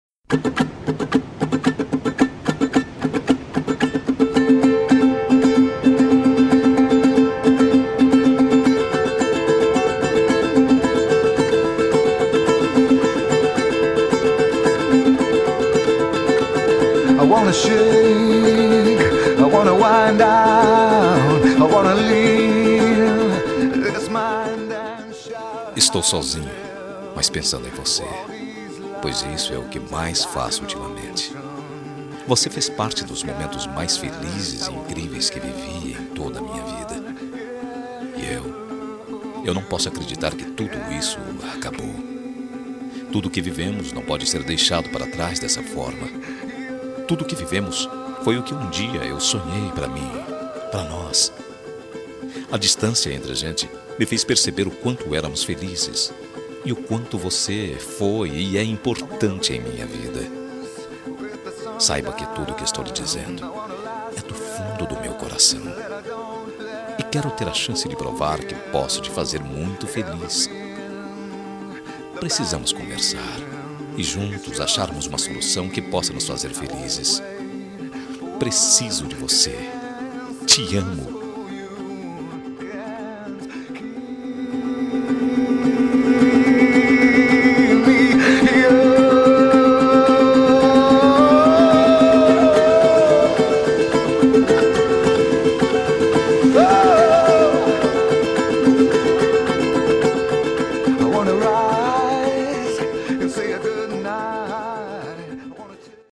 Telemensagem de Reconciliação Romântica – Voz Masculina – Cód: 202021